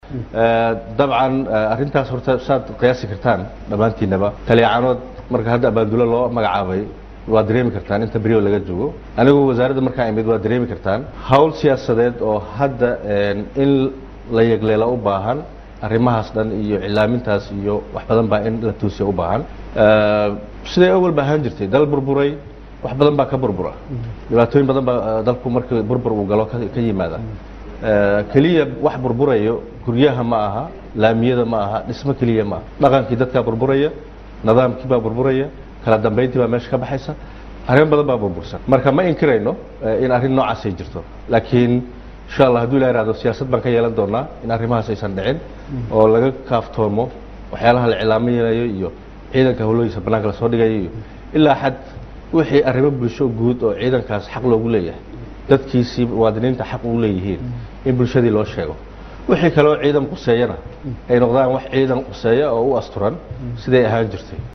DHAGEYSO COD: Xogta ciidanka oo la ilaalinayo iyo wasiirka Gaashaandhiga oo ka hadlay | Goobsan Media Inc
dhageyso-codka-wasiirka.mp3